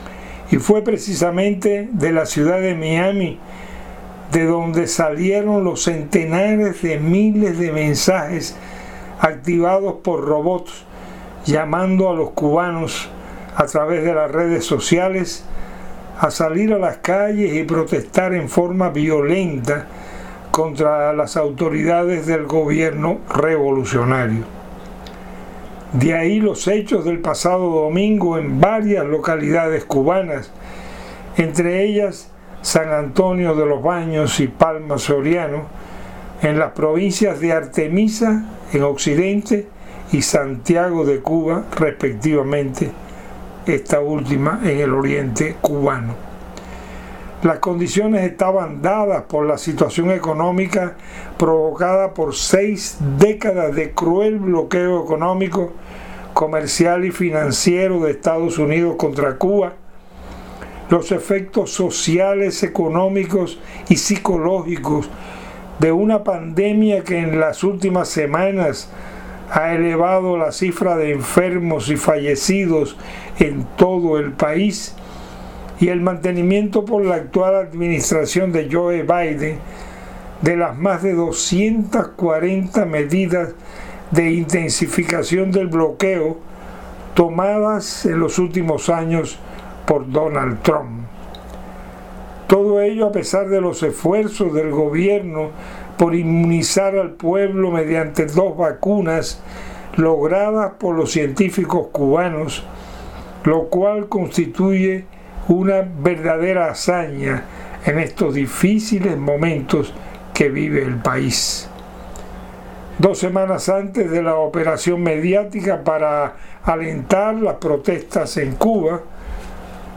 Entrevistas radiofônicas